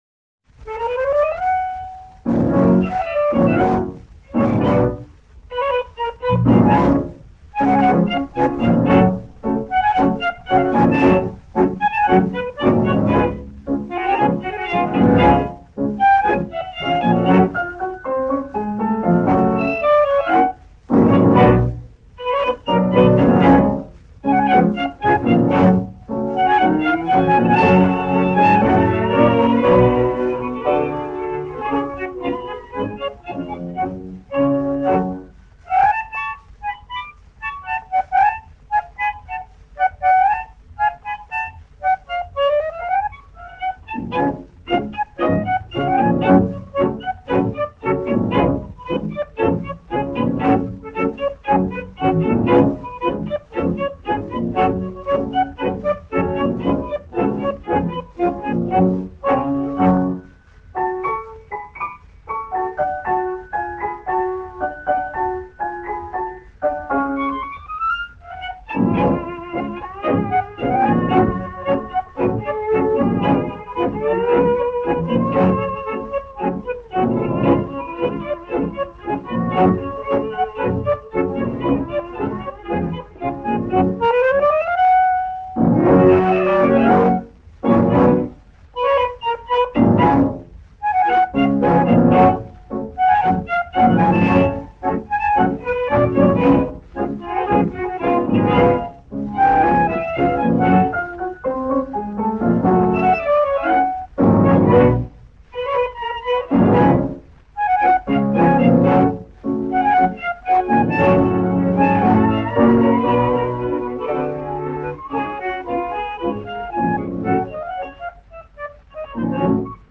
Танго
Instrumental